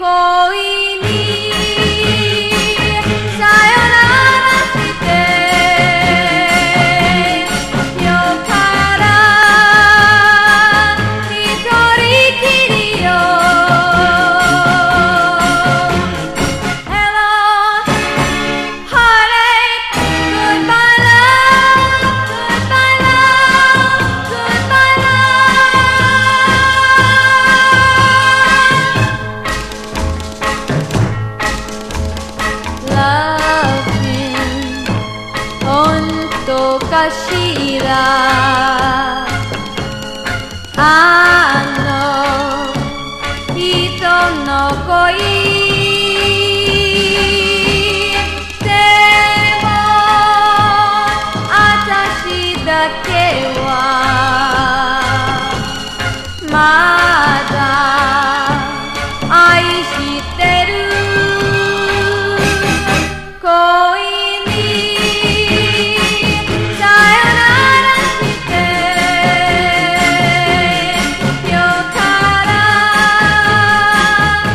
EASY LISTENING / OTHER / OLDIES / VOCAL / ROCK
オールディーズ・ダンス・クラシック！
踊れます。